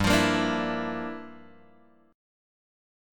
G Minor 13th